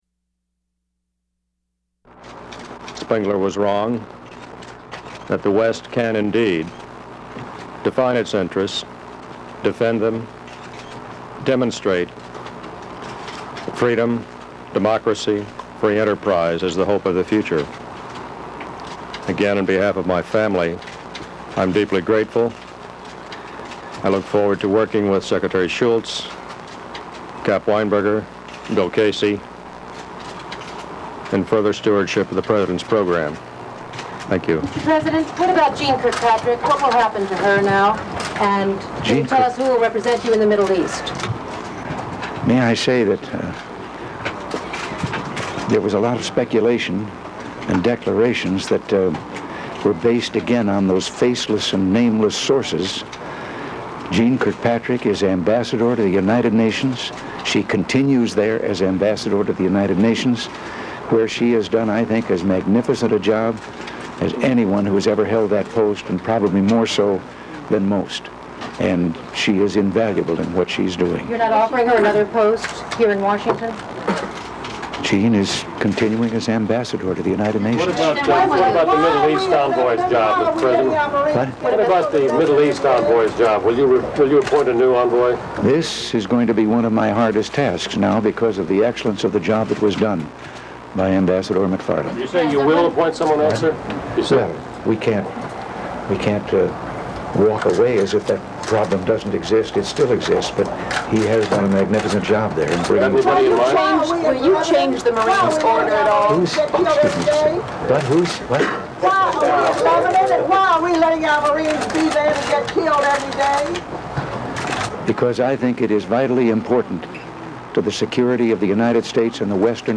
U.S. President Ronald Reagan announces the nomination of Robert McFarlane as National Security Advisor